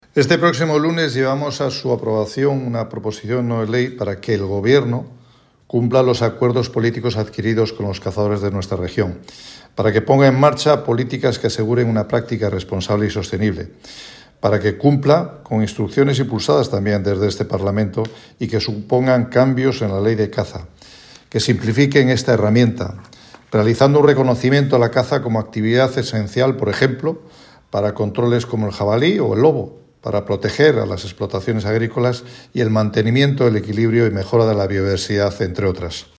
Ver intervención de Guillermo Blanco, diputado del Partido Regionalista de Cantabria y portavoz en materia de Medio Rural.